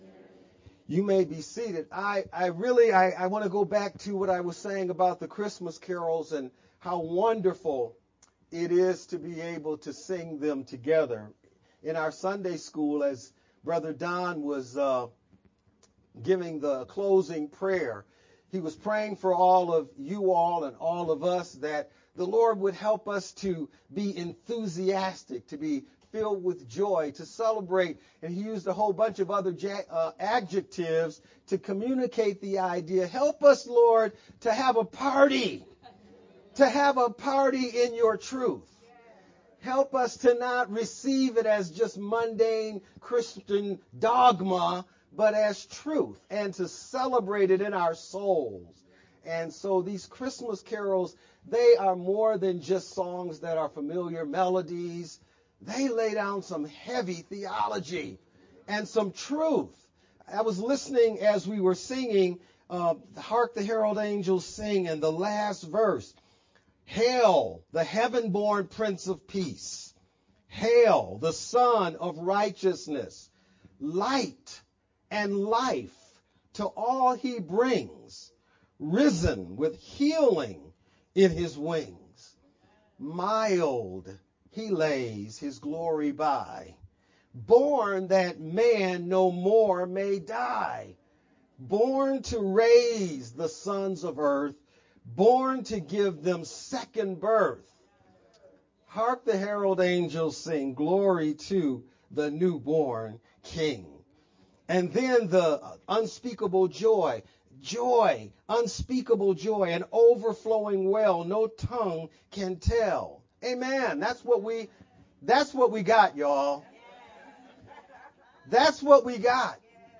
VBCC-Sermon-Sermon-edited-12-17-Made-with-Clipchamp_Converted-CD.mp3